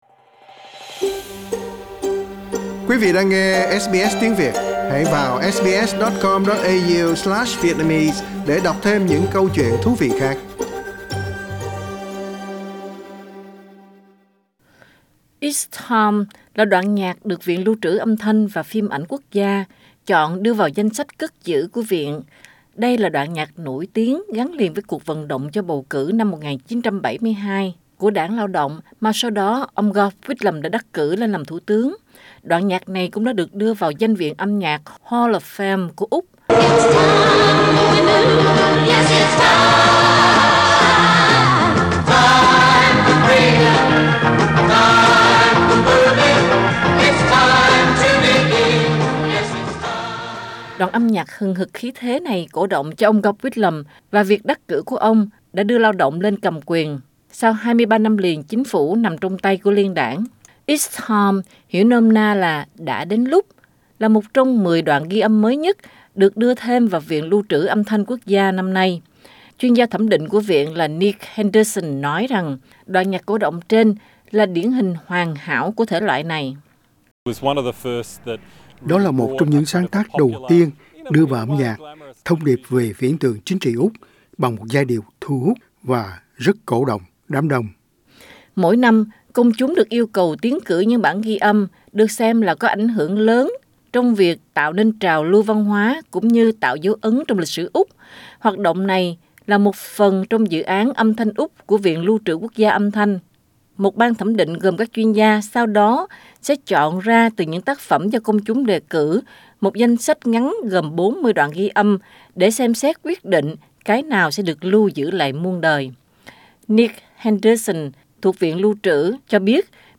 Năm nay 10 tác phẩm được chọn đưa vào viện lưu trữ Âm Thanh Quốc gia Úc để lưu giữ cho muôn đời sau. Xin lưu ý trong bài có sử dụng tiếng nói âm thanh những người đã khuất có thể rất nhạy cảm với những người gốc Thổ dân và dân đảo Torrest Strait.